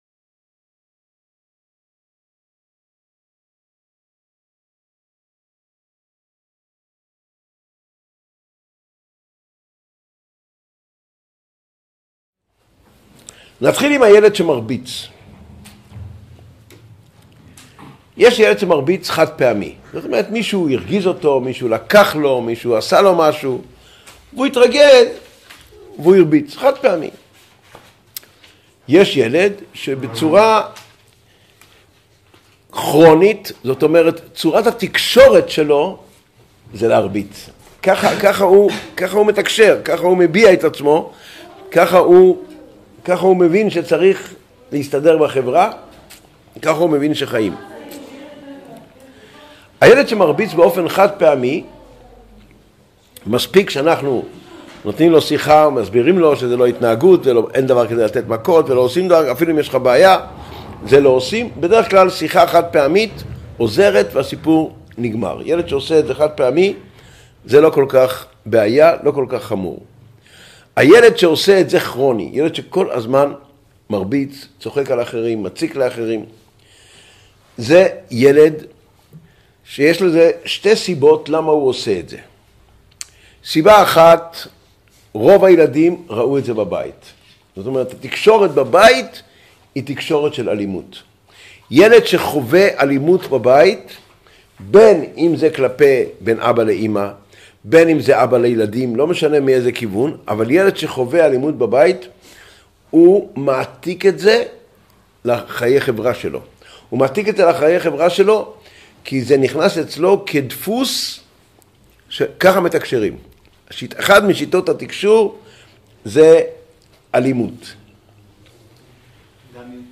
Воспитание детей Урок № 55 Почему ребенок дерется?